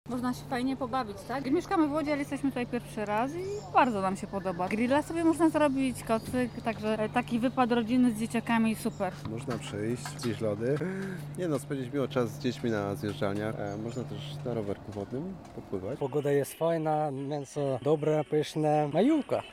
– Można przyjść na lody, spędzić miło czas z dziećmi na zjeżdżalniach, można też na rowerku wodnym popływać – dodaje łodzianin.